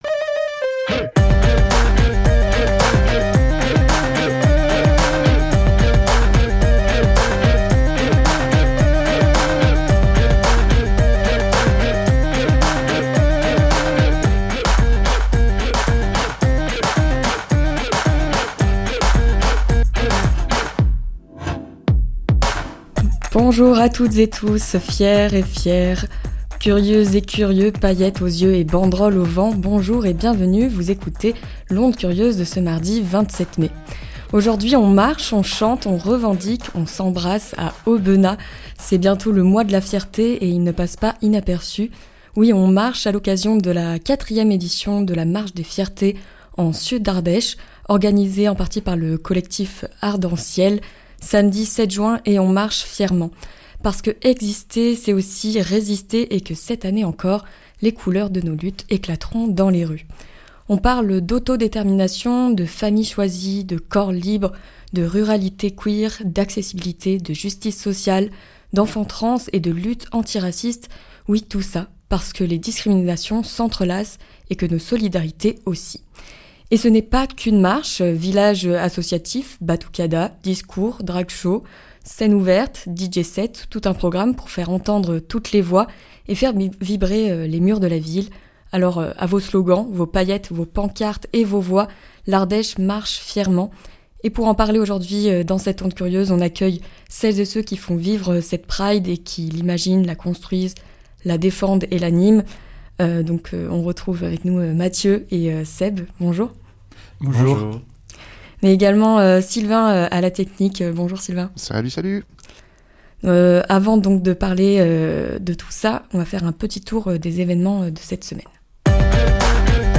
L'Onde Curieuse palpe, inspecte et détecte les soubresauts de la vie associative et culturelle locale et vous en parle tous les lundis, mardis et jeudis à midi ! La recette qui va bien : un agenda local suivi d'une interview d'une vingtaine de minutes.